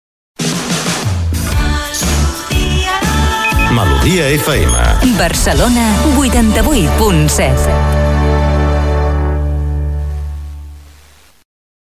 Identificació de l'emissora a Barcelona i freqüència.